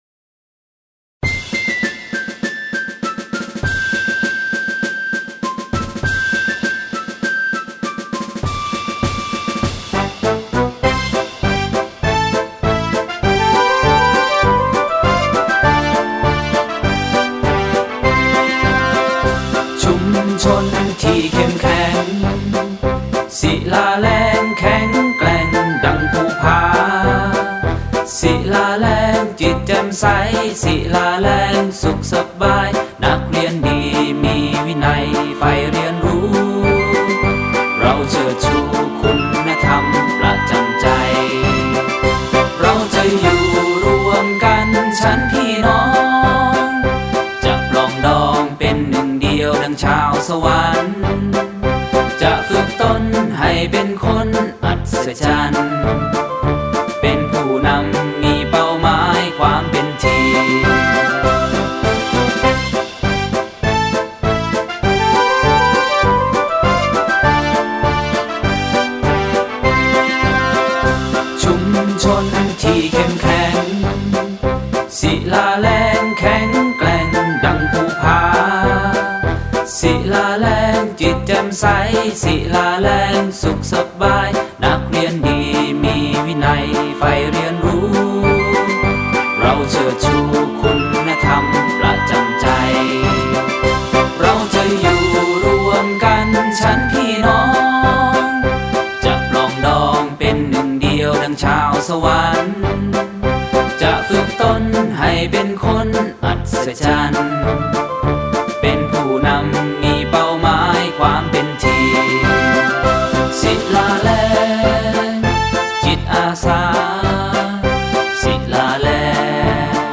เพลงมาร์ช โรงเรียนชุมชนศิลาแลง